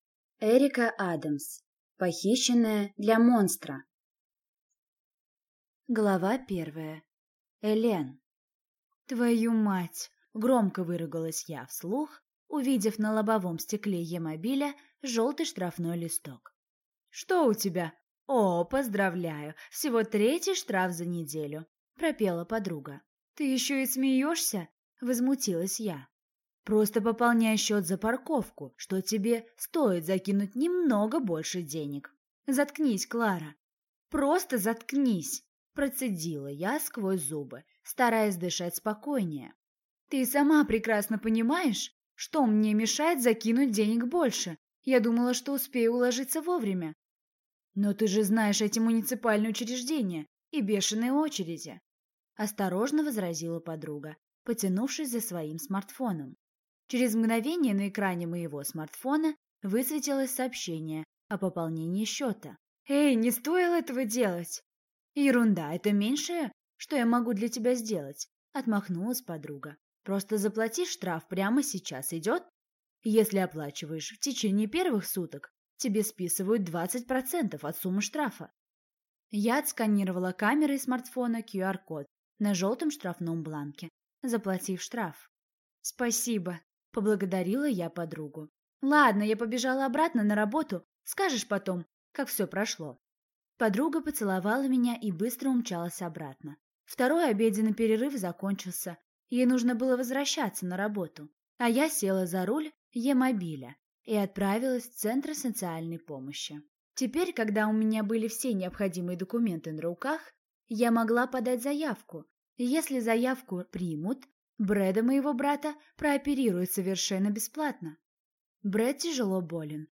Аудиокнига Похищенная для монстра | Библиотека аудиокниг